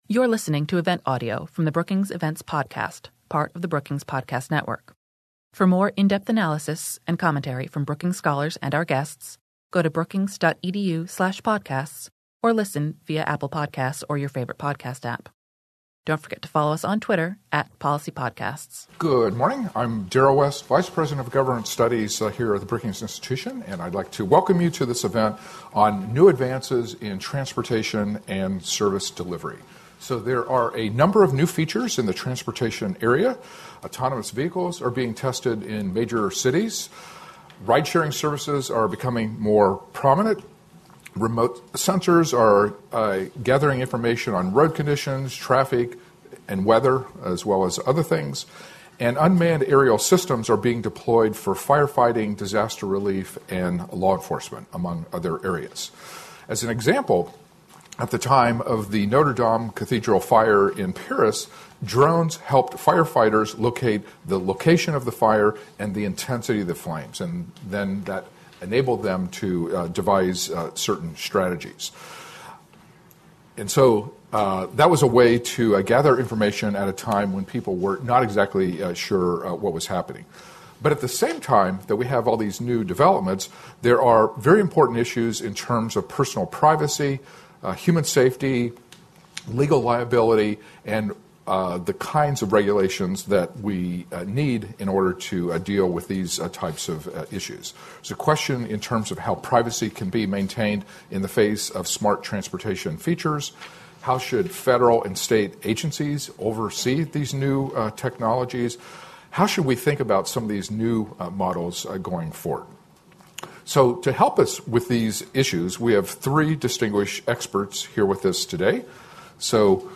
On September 10, the Center for Technology Innovation at Brookings hosted an event exploring new advances in transportation and service delivery.
Expert panelists discussed how to think about policy, law, and regulation in these developments.
Speakers answered questions from the audience after the discussion.